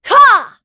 Isolet-Vox_Attack5_kr.wav